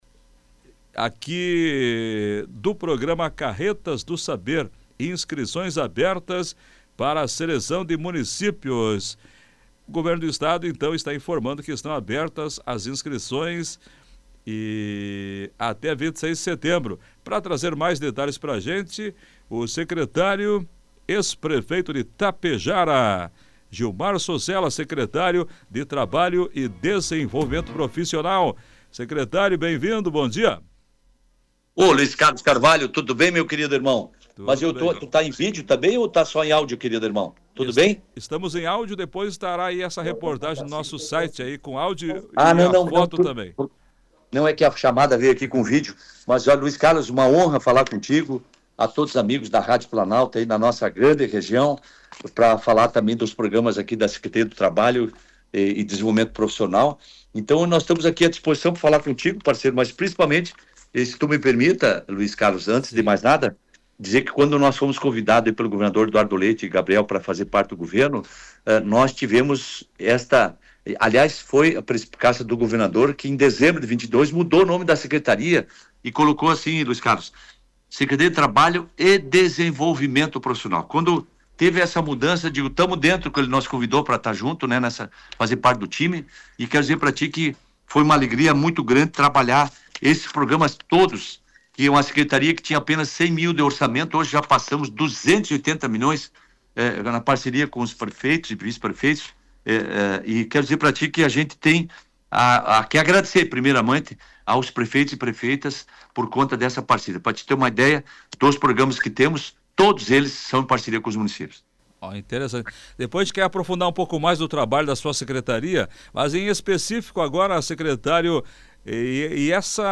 Entrevista: secretário estadual Gilmar Sossella fala sobre carretas que levarão formação pelo RS